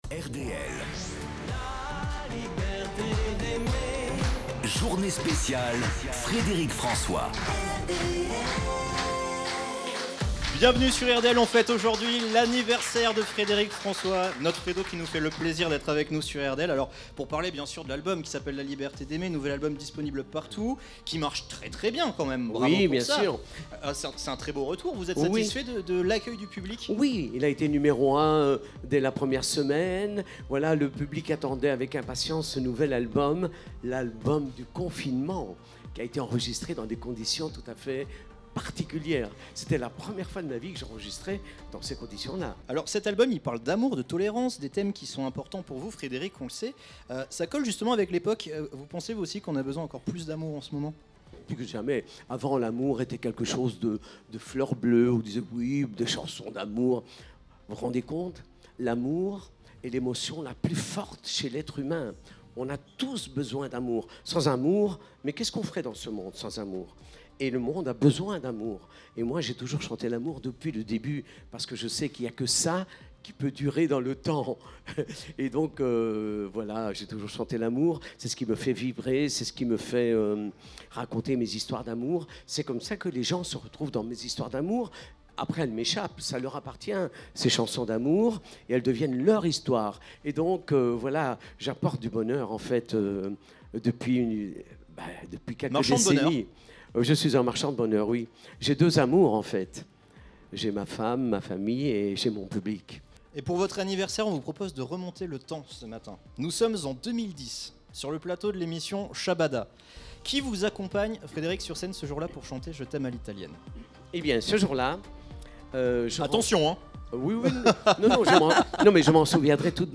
A l'occasion de son anniversaire, Frédéric François était l'invité de la matinale ce Jeudi 03 Juin.
interview